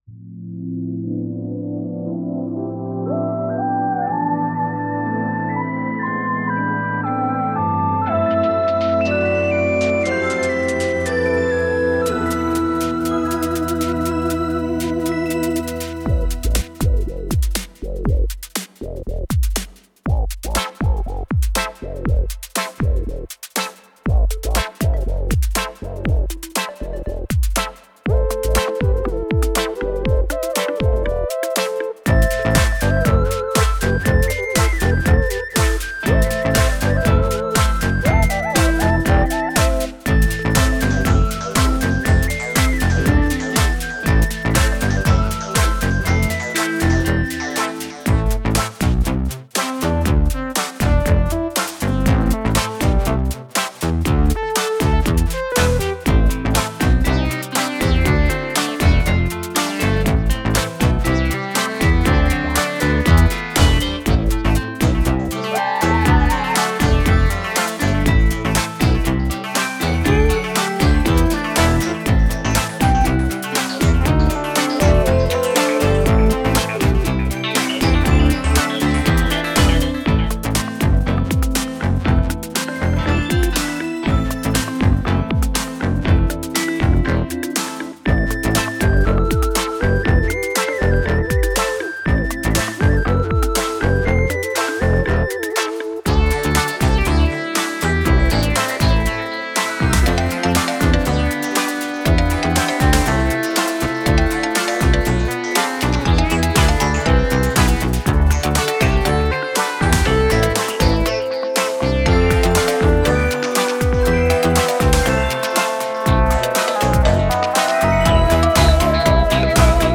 This straight forward, groovy pop song is a good showcase